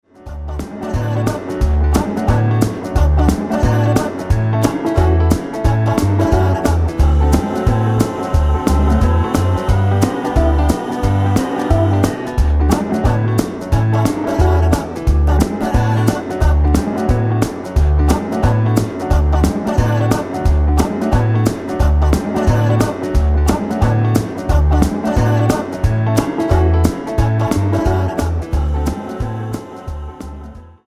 --> MP3 Demo abspielen...
Tonart:D mit Chor